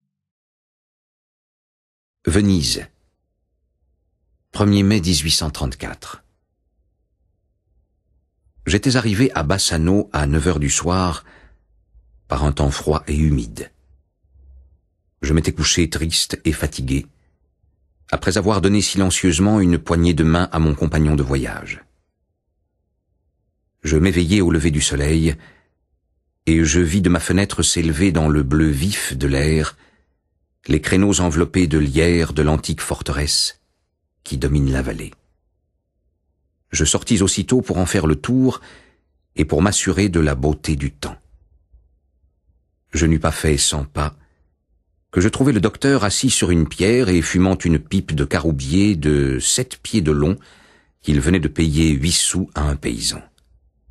Ces deux lettres, l’une à Alfred de Musset l’autre à Franz Liszt, nous font découvrir des paysages et des villes d’Italie mais aussi les opinions de l'auteur sur l’engagement social, la vie, l’art, l’amour... En intermède, le virtuose François-René Duchâble nous offre le plaisir d’interpréter Consolation de Franz Liszt.